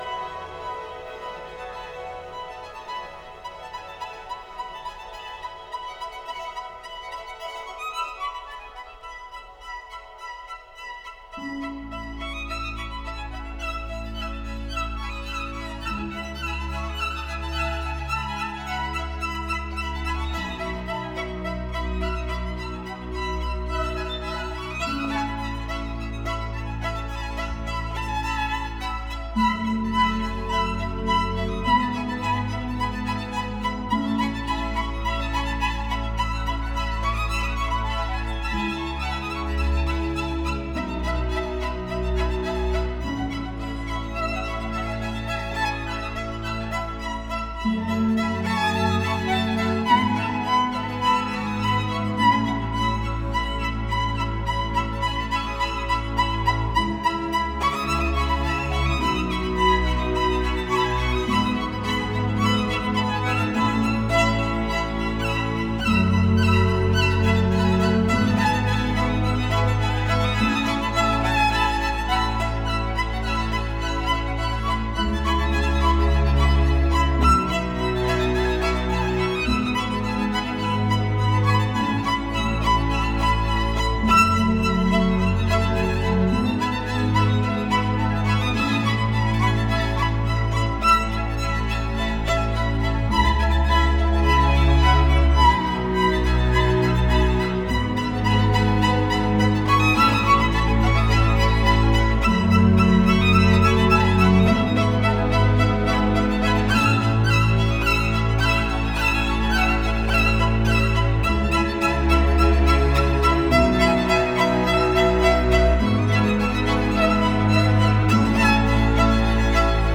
О современной классической музыке.